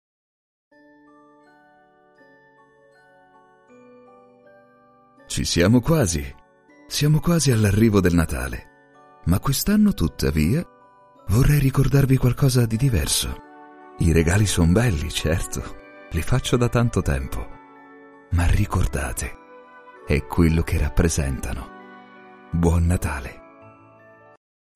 Native Voice Samples
Narration
BaritoneBassDeepLow
WarmEmotionalEmotiveStrongEmpathicVersatileNaturalFriendlyEngagingDarkCorporateCharming